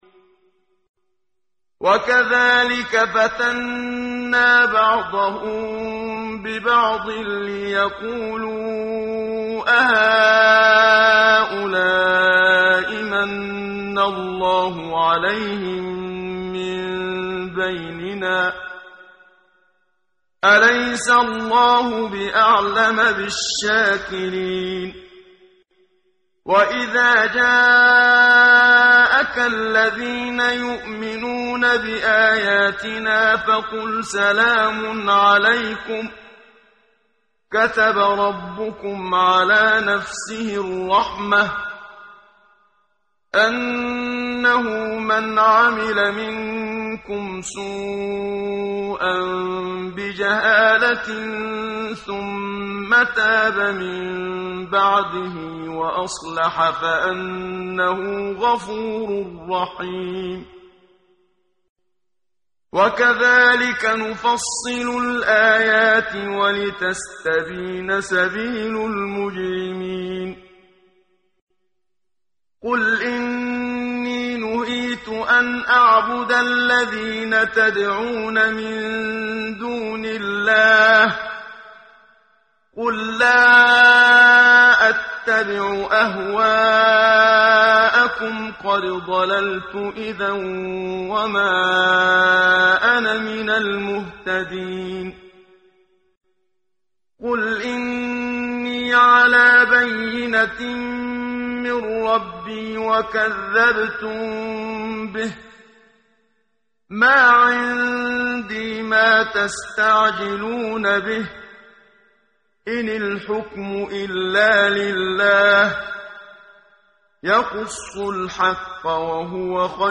ترتیل صفحه 134 سوره مبارکه انعام (جزء هفتم) از سری مجموعه صفحه ای از نور با صدای استاد محمد صدیق منشاوی